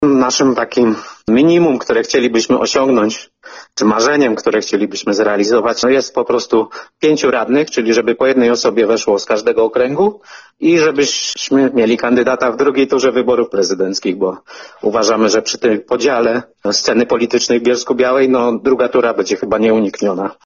Ta kolejność jest nieprzypadkowa, bo będzie u nas na listach sporo osób bezpartyjnych, niezaangażowanych w żadną partię – komentował dla nas poseł Bronisław Foltyn, który jednocześnie potwierdził, że będzie się w Bielsku-Białej ubiegał o prezydenturę.